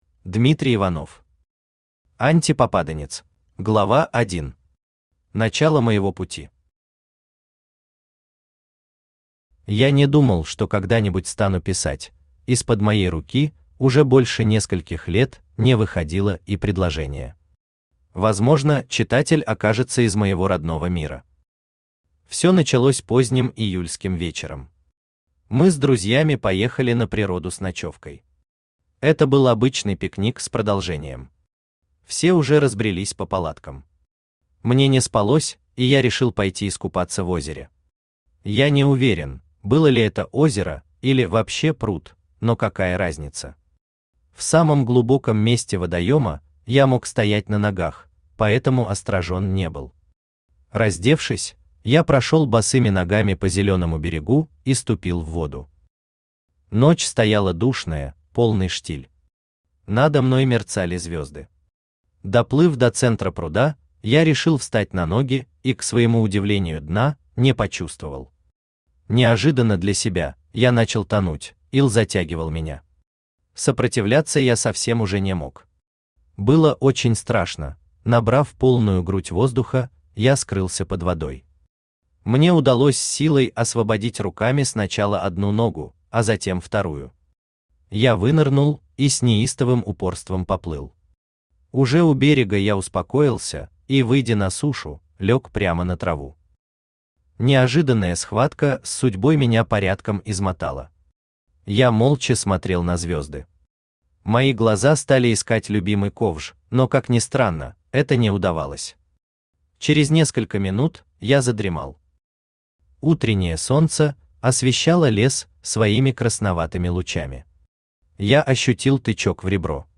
Аудиокнига АнтиПопаданец | Библиотека аудиокниг
Aудиокнига АнтиПопаданец Автор Дмитрий Иванов Читает аудиокнигу Авточтец ЛитРес.